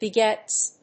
/bɪˈgɛts(米国英語), bɪˈgets(英国英語)/